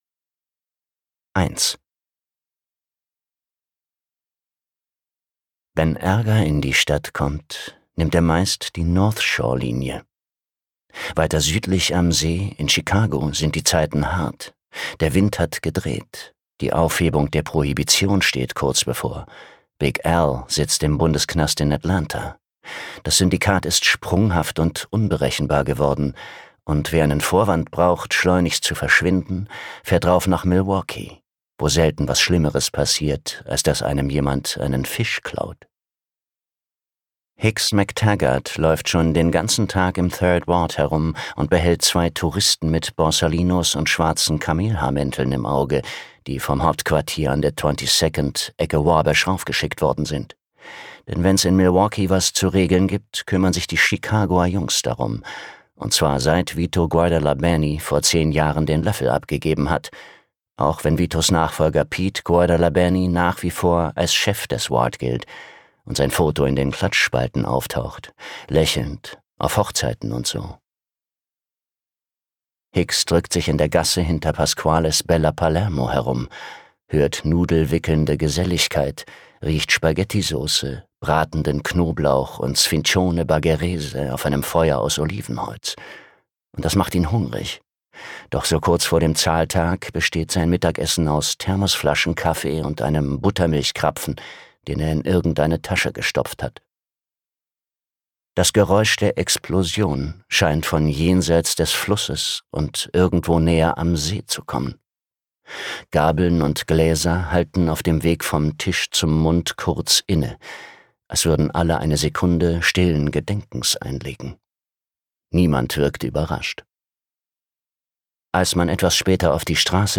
Gekürzt Autorisierte, d.h. von Autor:innen und / oder Verlagen freigegebene, bearbeitete Fassung.